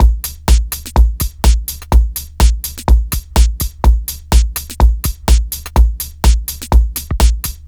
Сэмплы ударных (Дэнс Электро): Shoemaker B
Тут вы можете прослушать онлайн и скачать бесплатно аудио запись из категории «Dance Electro № 2».